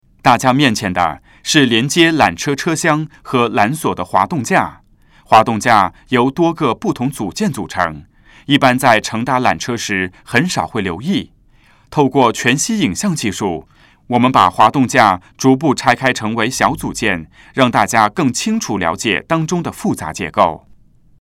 缆车探知馆语音导赏 (普通话)